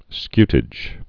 (skytĭj)